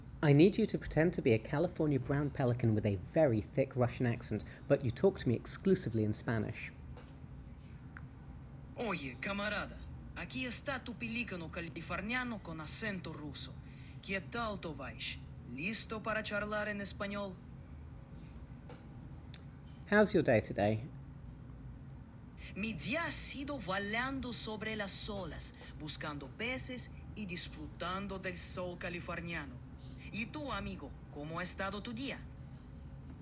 an MP3 lying around from a previous experiment which mixes English and Spanish.
russian-pelican-in-spanish.mp3